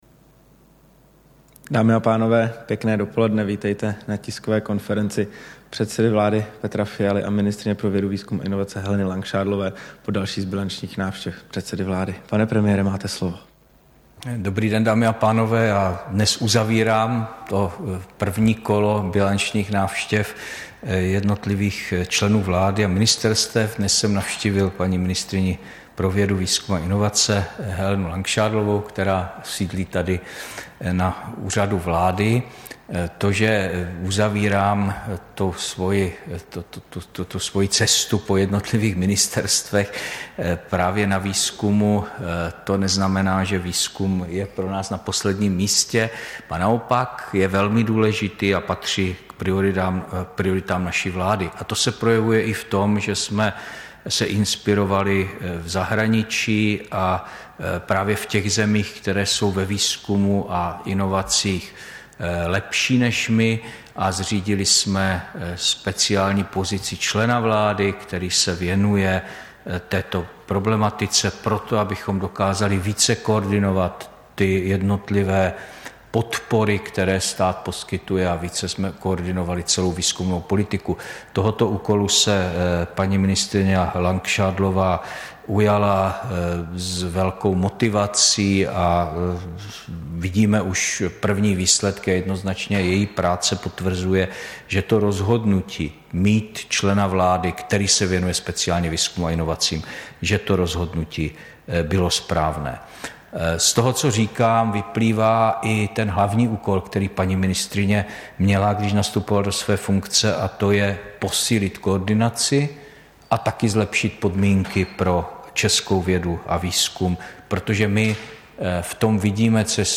Tisková konference po bilanční návštěvě premiéra Petra Fialy u ministryně pro vědu, výzkum a inovace Heleny Langšádlové, 27. dubna 2023